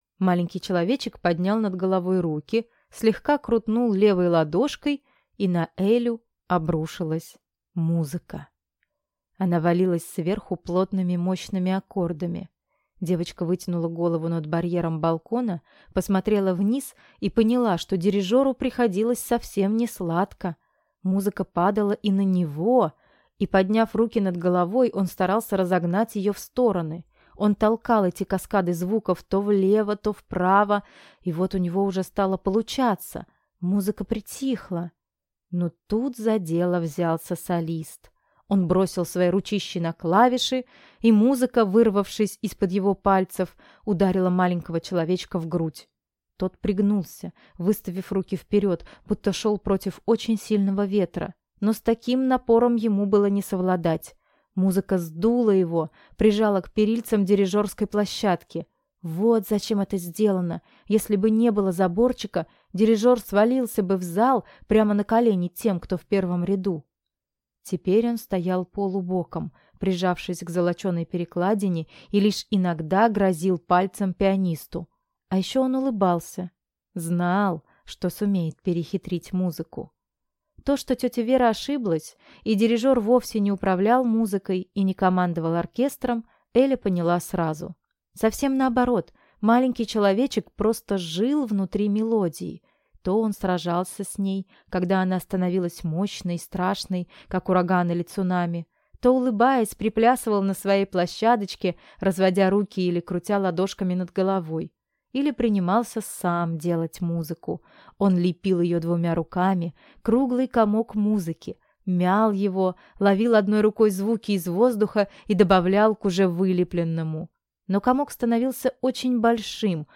Аудиокнига Реки текут к морю. Книга II. Каждой – свое | Библиотека аудиокниг